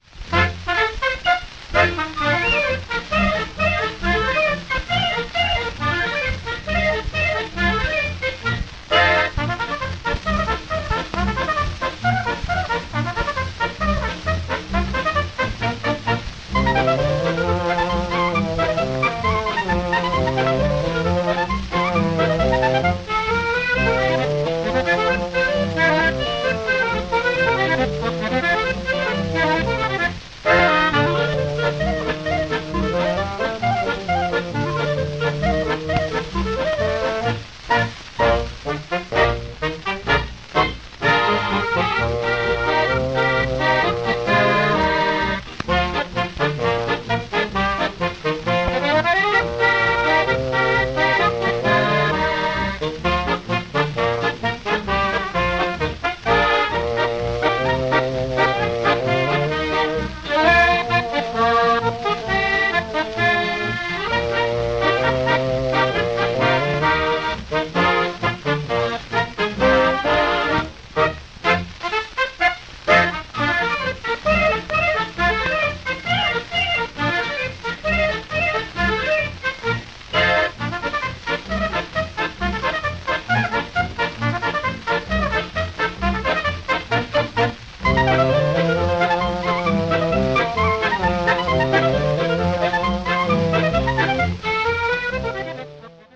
Когда, например, ансамбль с польским именем «Wesole chłopaki» записал инструментальную версию «Ойры-ойры» для американской аудитории, то возникла необходимость даже на этикетке грампластинки как-то растолковать американцам это странное для них название: «Ойра-ойра» — это-де, господа хорошие, знакомое вам «Yippee-Yippee» (скачать):